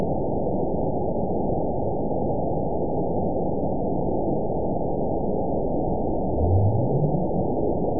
event 920525 date 03/28/24 time 22:06:09 GMT (1 year, 1 month ago) score 9.31 location TSS-AB02 detected by nrw target species NRW annotations +NRW Spectrogram: Frequency (kHz) vs. Time (s) audio not available .wav